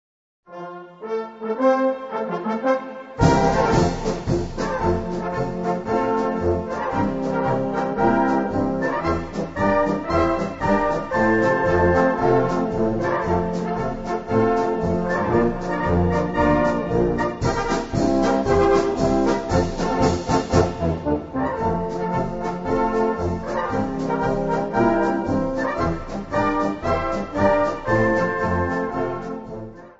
Gattung: Straßenmarsch Besetzung: Blasorchester Tonprobe